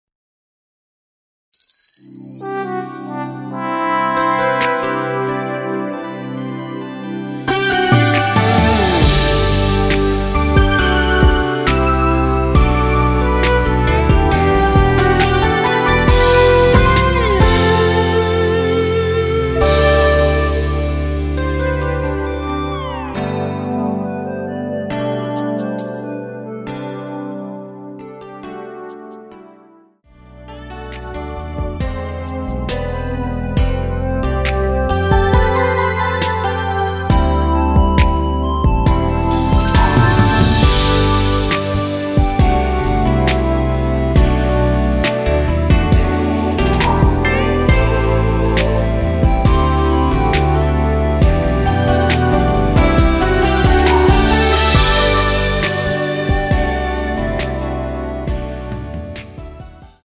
원키(짧은편곡) 멜로디 포함된 MR입니다.
앞부분30초, 뒷부분30초씩 편집해서 올려 드리고 있습니다.
중간에 음이 끈어지고 다시 나오는 이유는